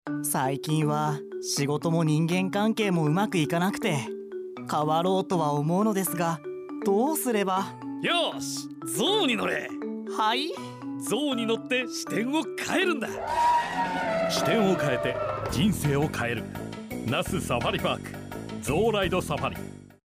「おもしろCM」